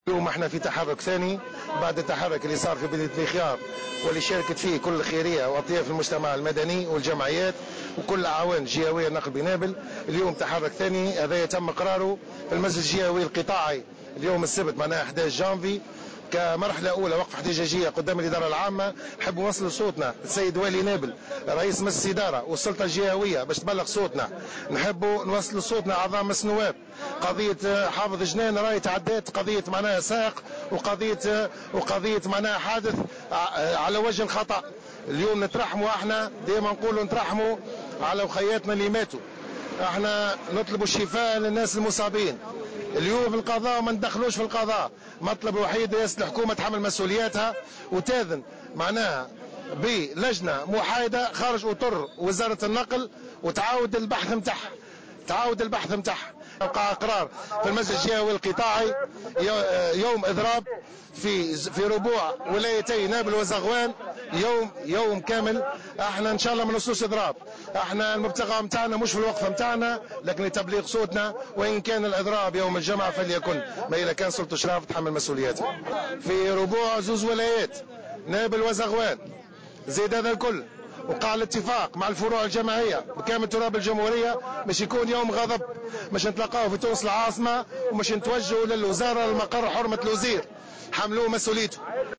وأضاف في تصريح لمراسلة "الجوهرة أف أم" على هامش وقفة احتجاجية انتظمت اليوم أمام الشركة الجهوية للنقل بنابل أنه لا يمكن تحميل المسؤولية للسائق، داعيا رئاسة الحكومة إلى تشكيل لجنة تحقيق محايدة خارج أطر وزارة النقل وإعادة البحث في القضية، وفق تعبيره.